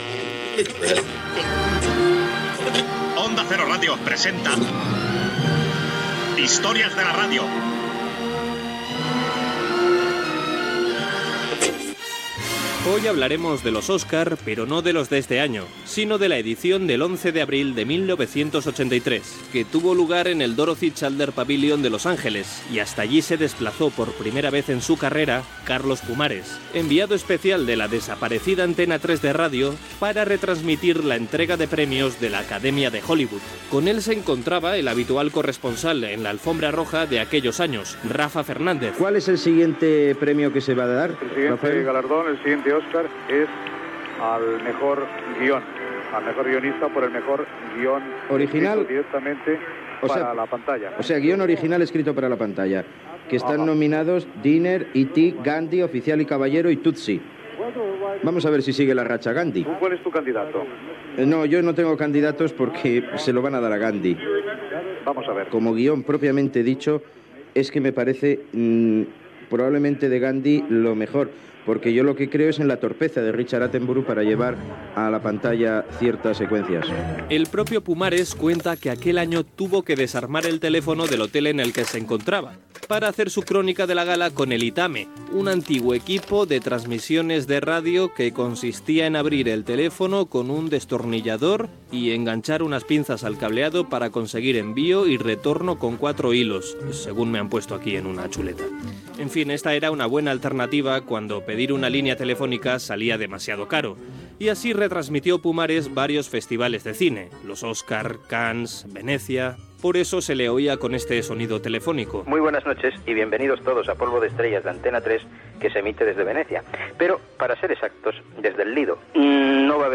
Info-entreteniment
FM
Programa presentat per Carlos Alsina.